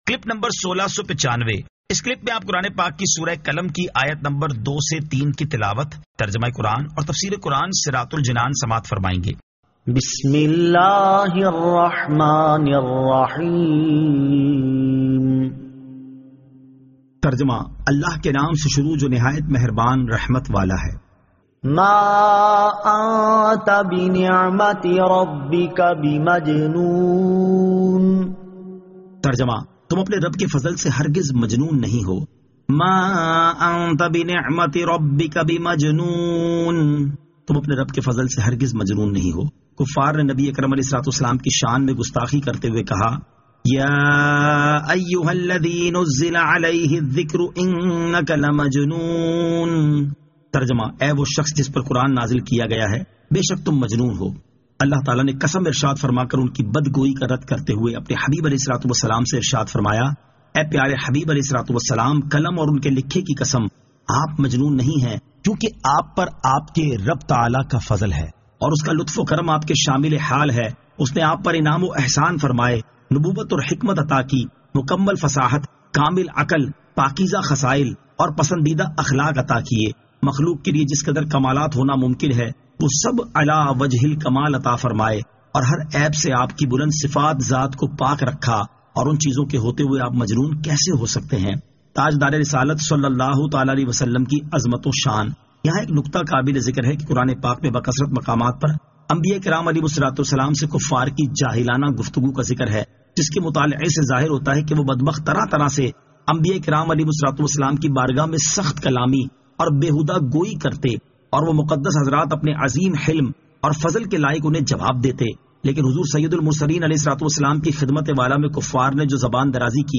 Surah Al-Qalam 02 To 03 Tilawat , Tarjama , Tafseer